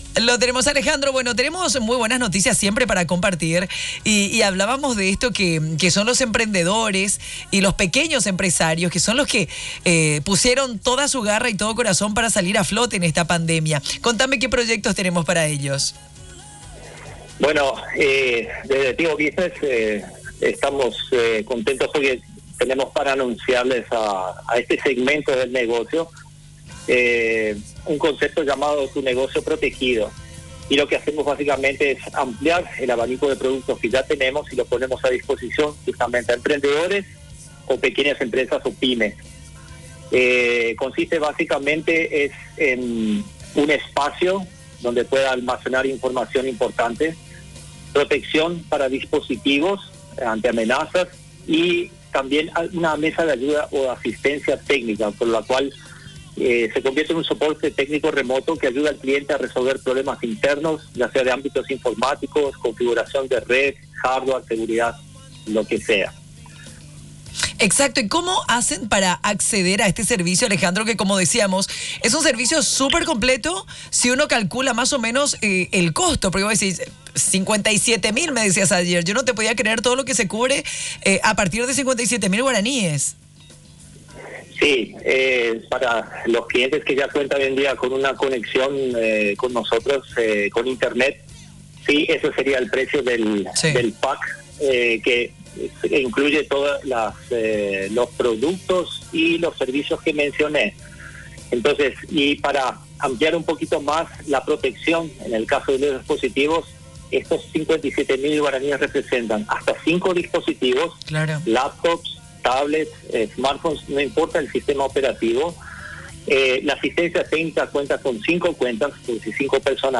ENTREVISTA-TIGO-MUNDO-ASPEN1.mp3